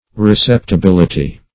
Search Result for " receptibility" : The Collaborative International Dictionary of English v.0.48: Receptibility \Re*cep`ti*bil"i*ty\ (r[-e]*s[e^]p`t[i^]*b[i^]l"[i^]*t[y^]), n. 1. The quality or state of being receptible; receivableness.